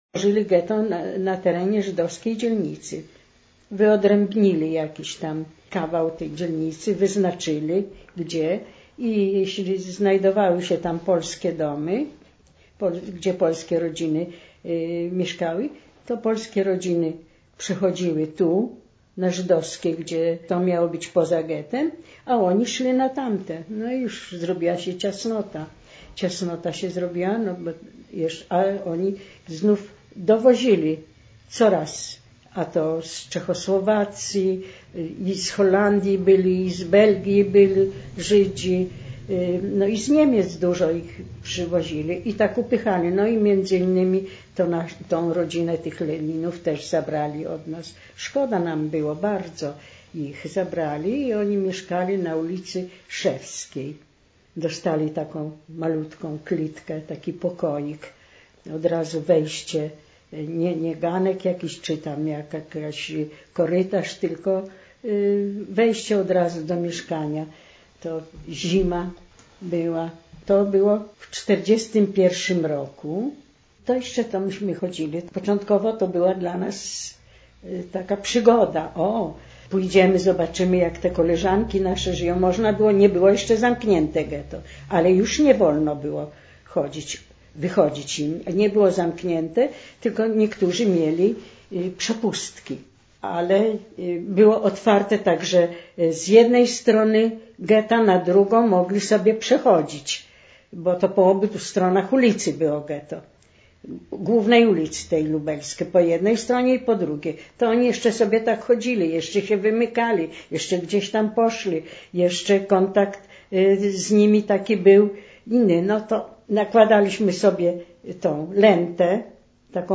fragment relacji świadka historii
Relacja mówiona zarejestrowana w ramach Programu Historia Mówiona realizowanego w Ośrodku